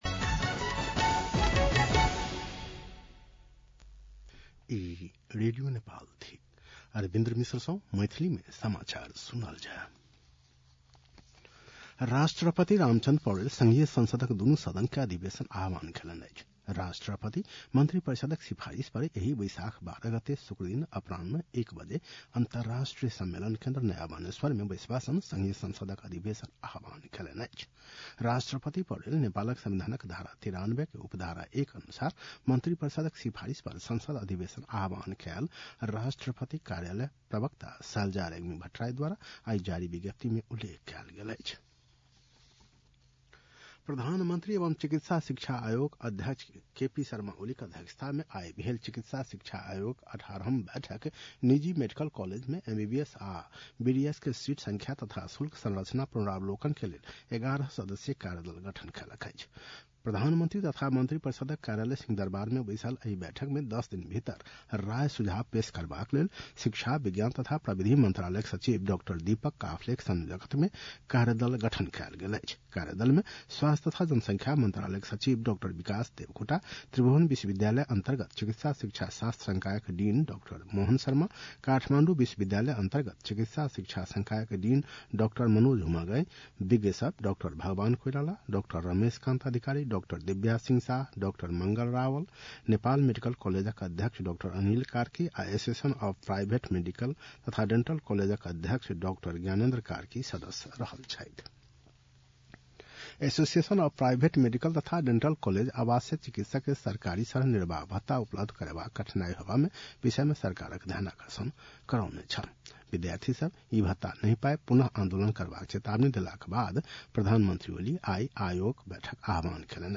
मैथिली भाषामा समाचार : ३ वैशाख , २०८२
Maithali-news.mp3